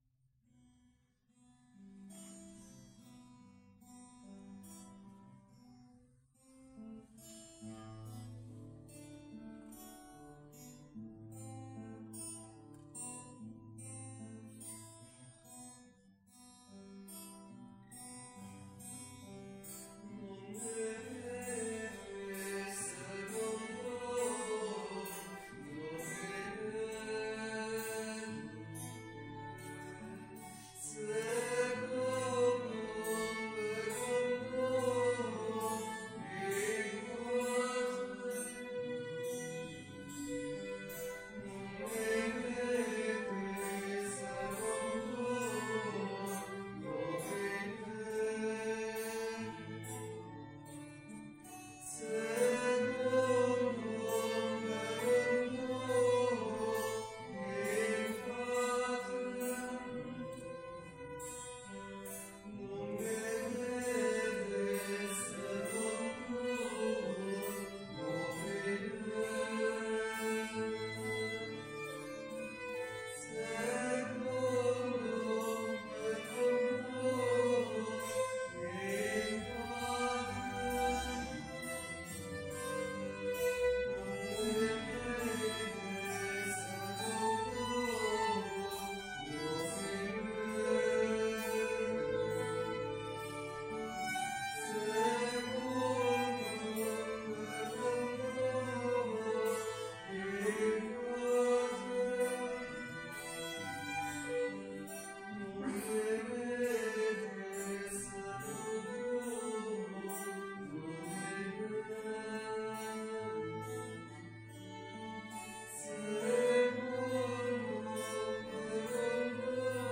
Pregària de Taizé a Mataró... des de febrer de 2001
Parròquia M.D. de l'Esperança - Diumenge 27 de març de 2022
Vàrem cantar...